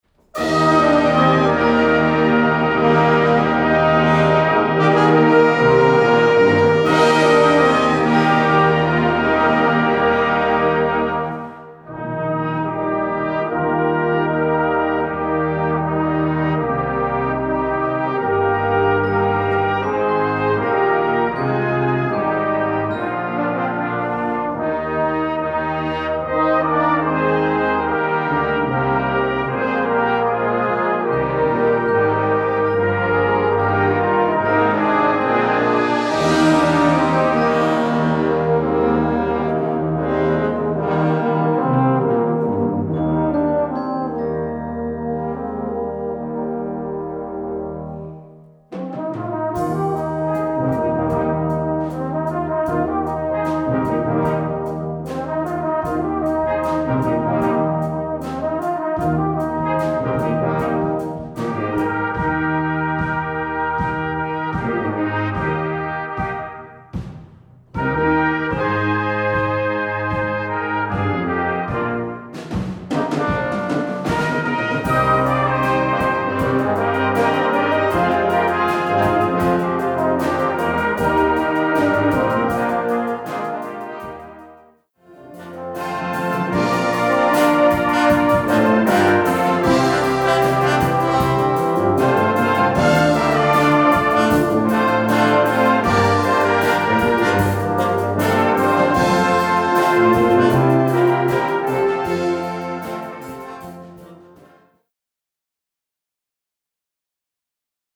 Gattung: Konzertwerk
3:20 Minuten Besetzung: Blasorchester Tonprobe